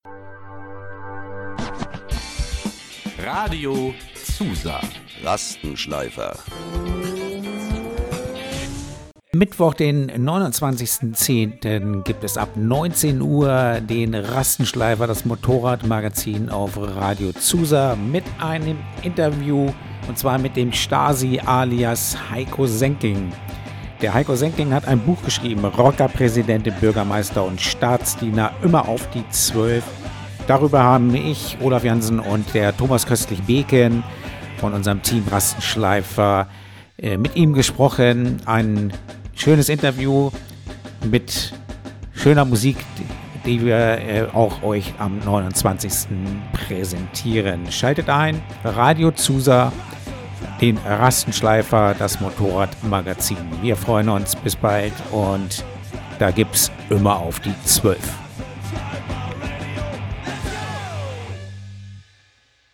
Mi., 29.10.2025 – Interview mit Stasi alias Heiko Senking